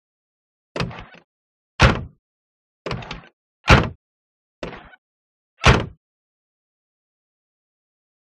Automobile; Door Open and Close; Alfa Romeo Doors Open And Close.